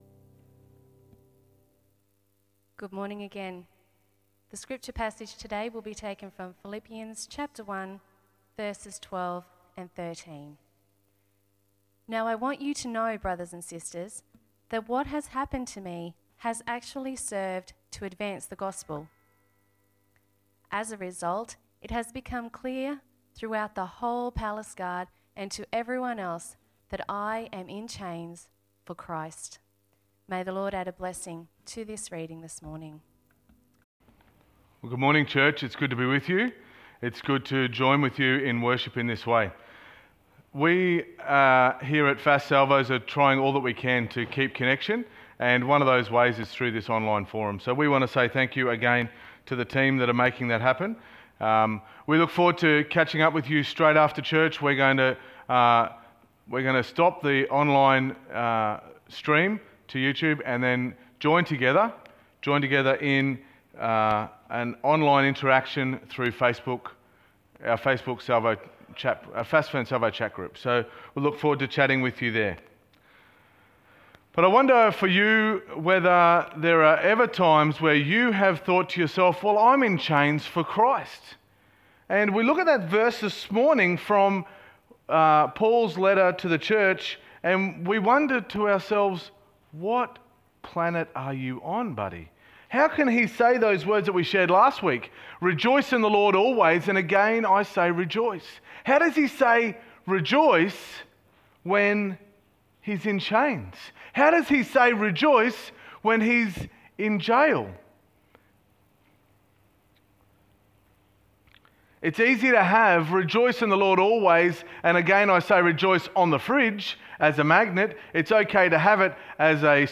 Sermon 29.03.2020